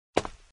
Warcorrespondents / Assets / 音效 / 走&跑 / 走路1.mp3
走路1.mp3